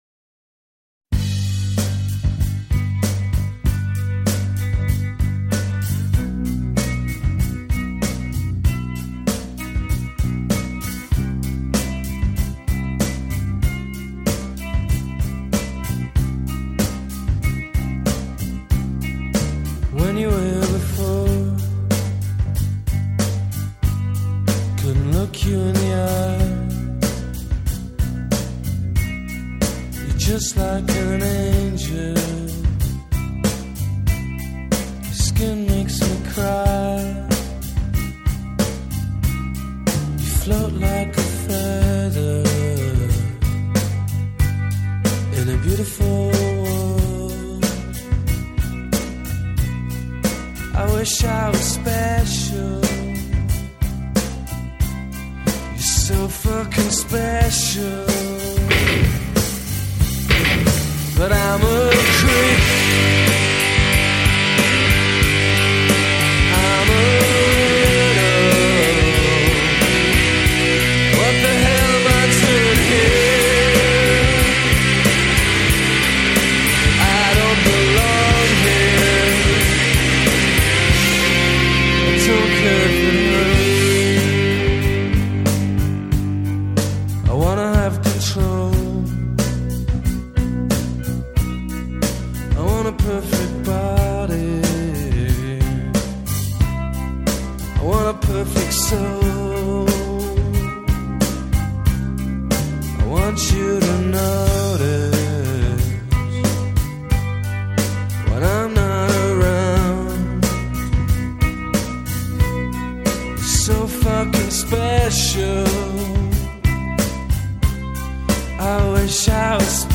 Жанр: Alternative